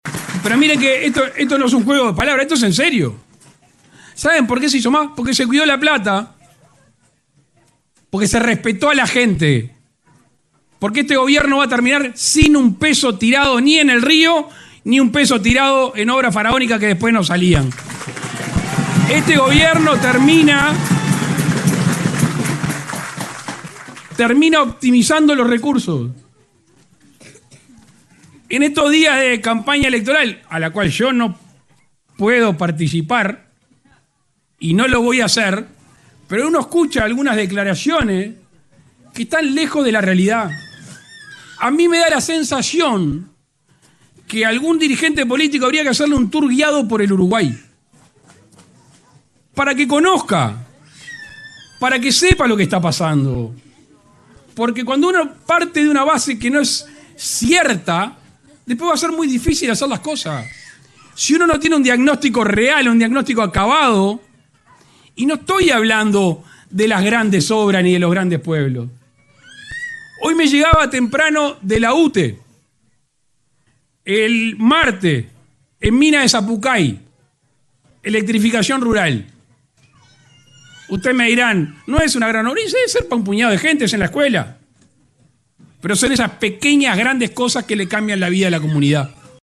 Lacalle Pou inauguró obras en la ruta n.° 30 entre Artigas y Tranqueras en el departamento de Rivera